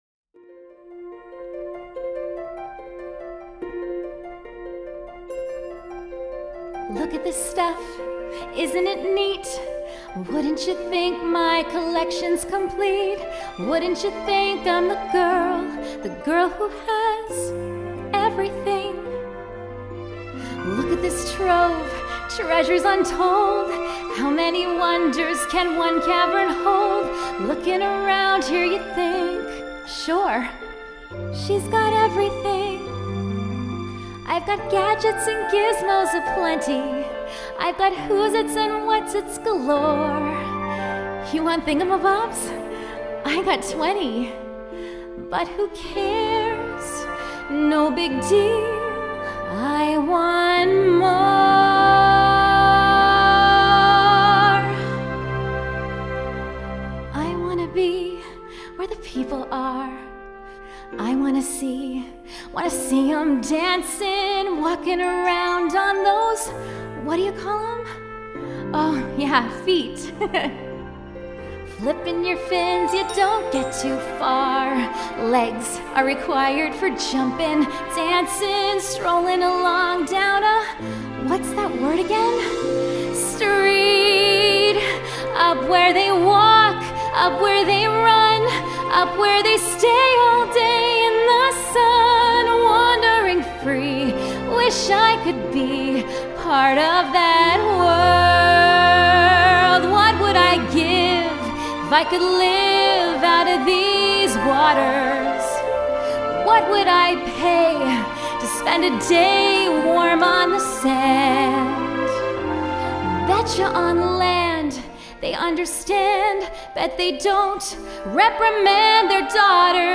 Female
I’m a Canadian voiceover artist with a native North American accent and a warm, youthful, relatable sound.
Microphone: Rode NT1-A